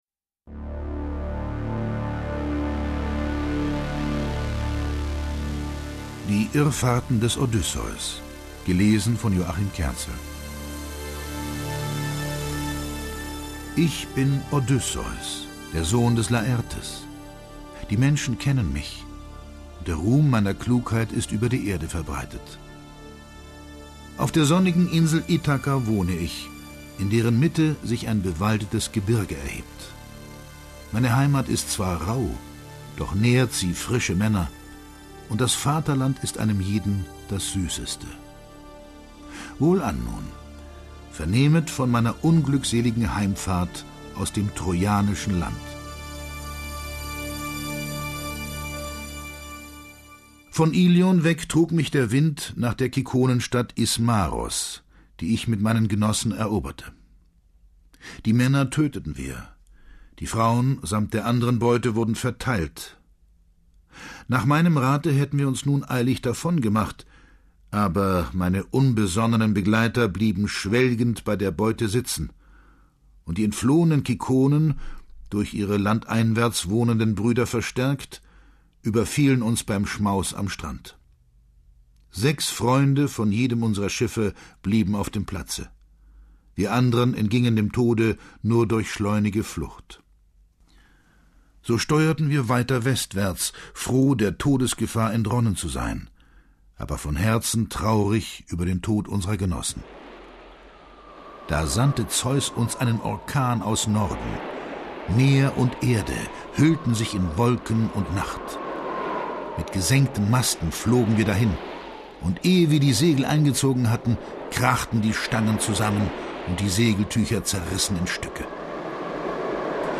Joachim Kerzel (Sprecher)
Joachim Kerzels Stimme, die er Filmgrößen wie Jack Nicholson, Anthony Hopkins oder Harvey Keitel leiht, ist legendär und macht ihn zu einem der begehrtesten Hörbuchsprecher Deutschlands.